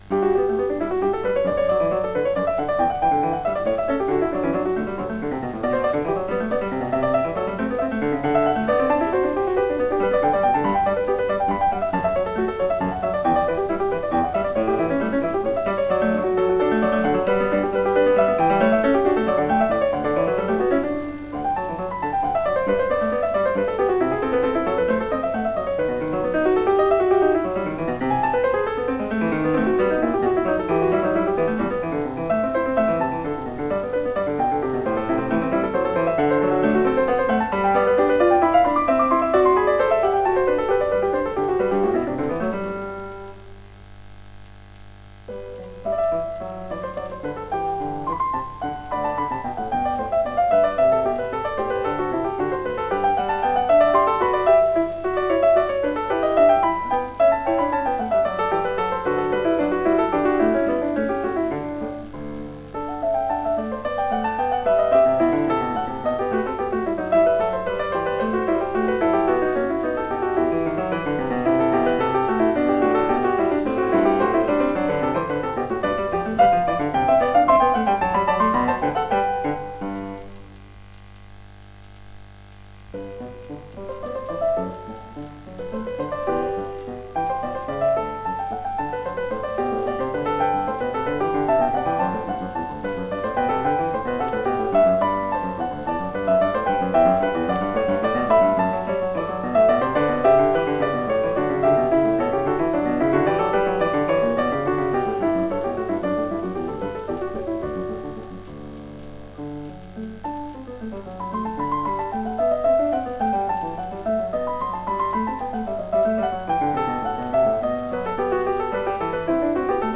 本作が録音された1955年当時、『ゴールドベルク変奏曲』は、ただややこしいだけの退屈な曲とされていた訳ですが、そのイメージを一新させたのが本作です！清冽で壮麗、魔法のような音の羅列には圧巻の美しさがありますね！
※レコードの試聴はノイズが入ります。